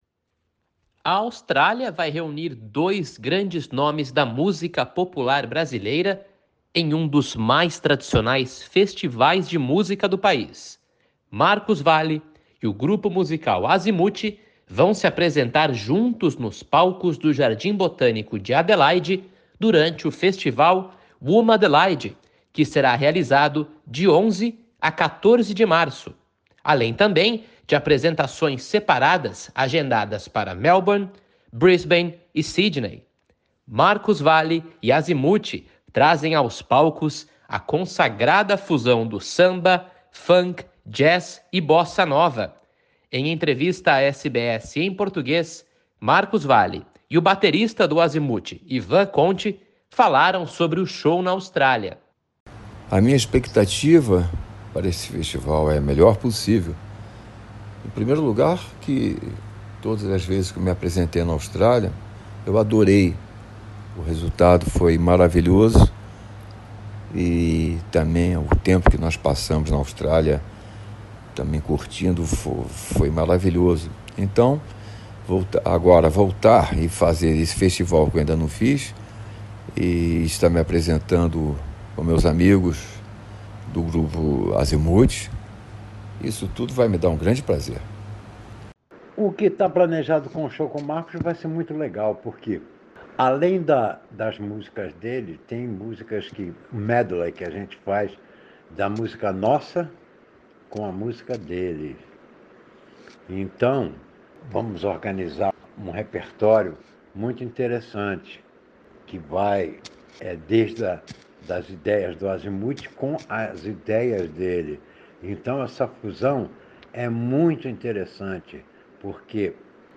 READ MORE Ex-guerrilheiros timorenses honram passado de luta através da música Em entrevista à SBS em Português , Marcos Valle e o baterista do Azymuth, Ivan Conti falaram sobre o show na Austrália.